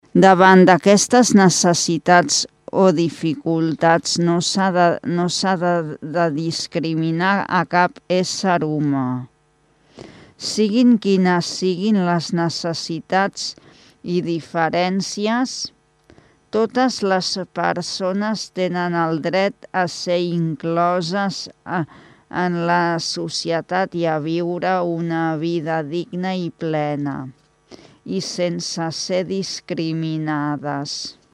Desenes de persones van omplir la Plaça de l’Ajuntament, sobretot per escoltar el manifest d’aquest any.